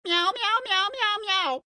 AV_cat_long.ogg